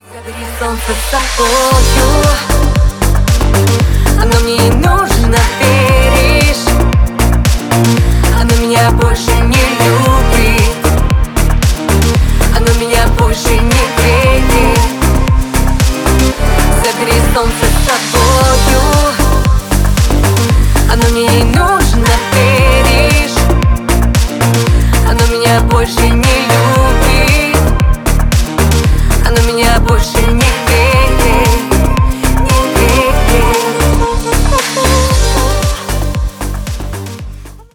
Поп Музыка
кавер